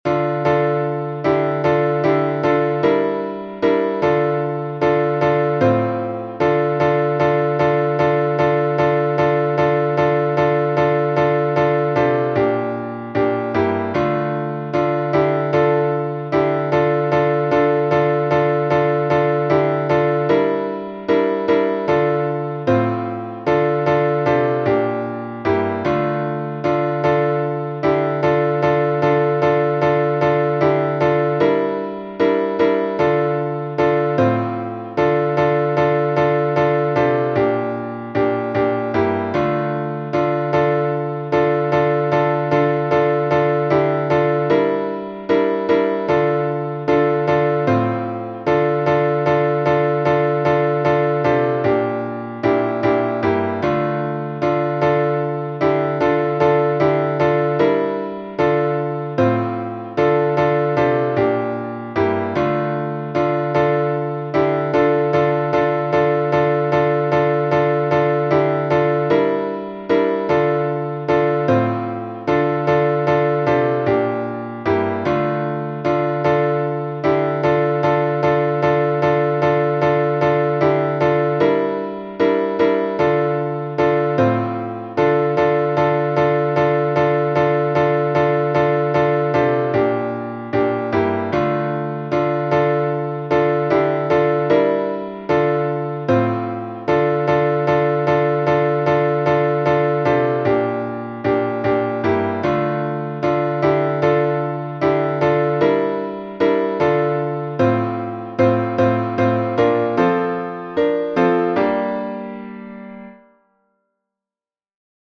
Киевский распев, глас 7